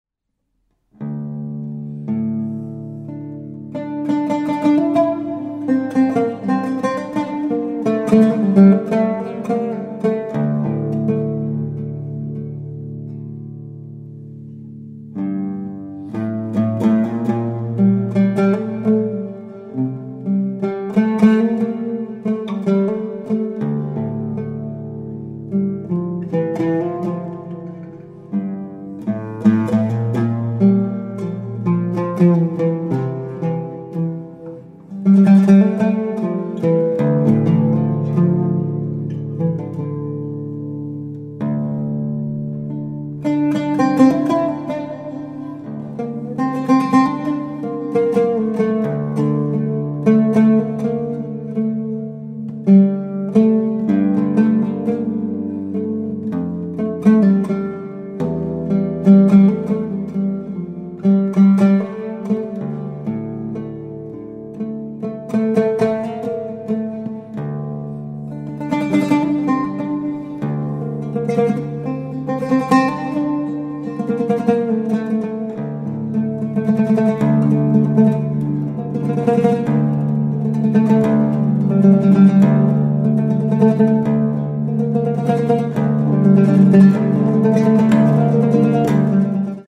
Contemporary , Japanese Ambience
, Oud , Relaxing / Meditative